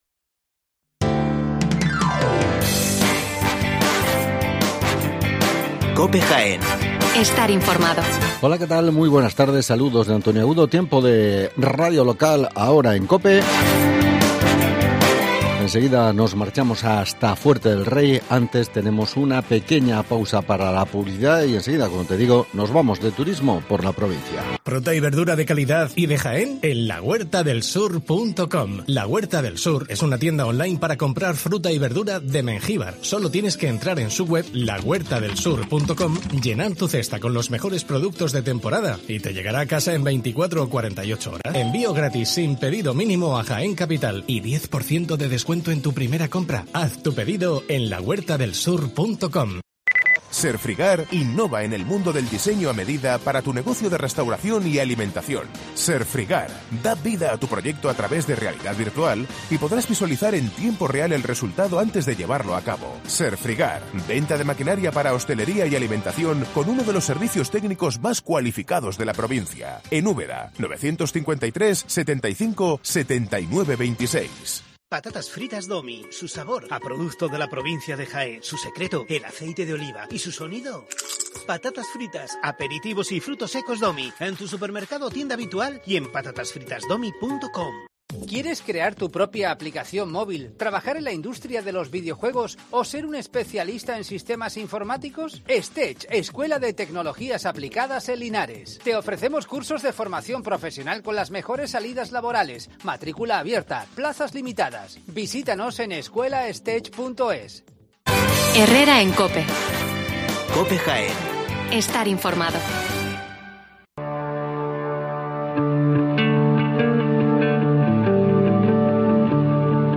AUDIO: Hablamos con el alcalde de Fuerte del Rey en Herrera en COPE